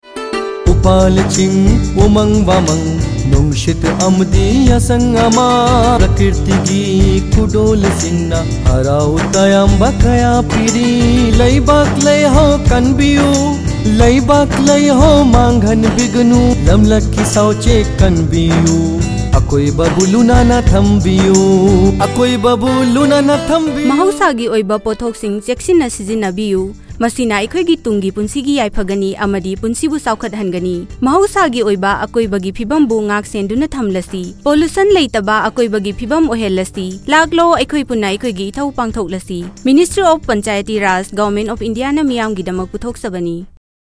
141 Fundamental Duty 7th Fundamental Duty Preserve natural environment Radio Jingle Manipuri